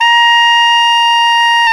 SAX TENORM1P.wav